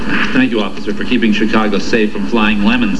"Thank you officer for saving Chicago from flying lemons"- From Dolly Parton's Straight Talk.